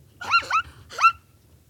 Звуки зебры
Голос зебры